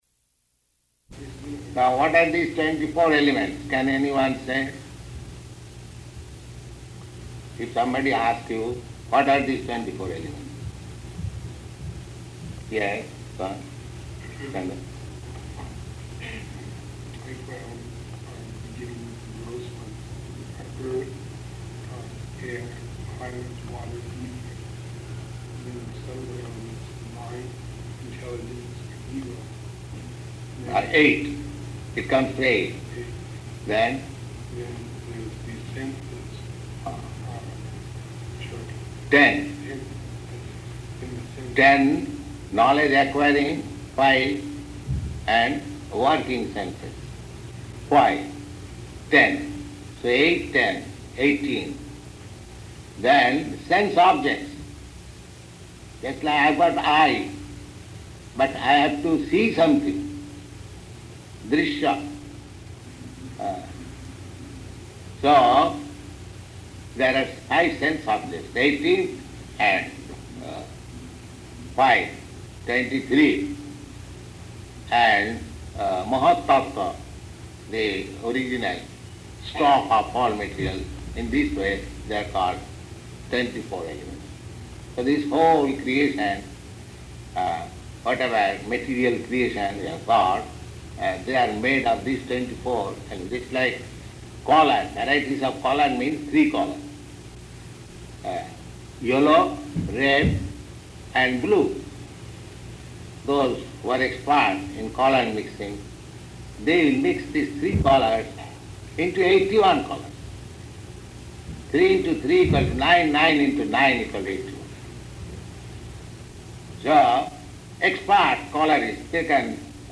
Lecture on Twenty-four Elements [partially recorded]
Location: Los Angeles